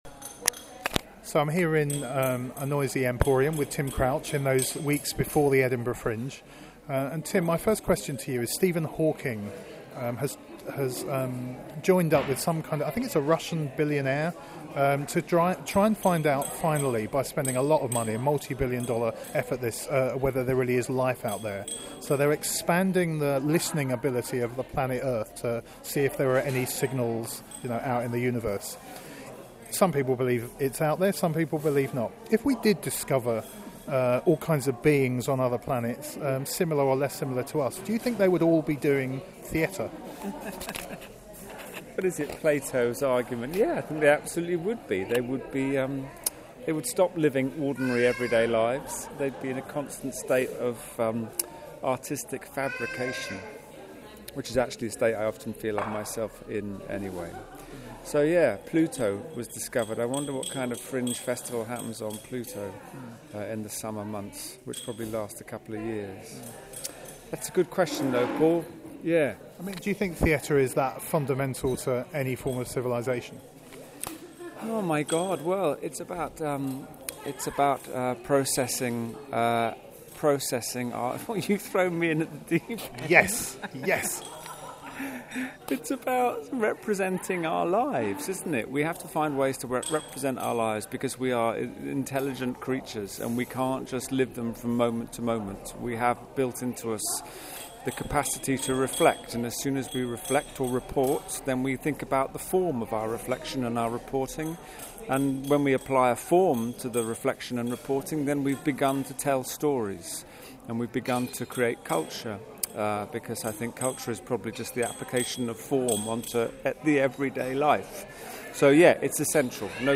Tim Crouch talks about this unique piece of theatre and reflects on the process of theatre itself. Listen to our interview with Tim Crouch about An Oak Tree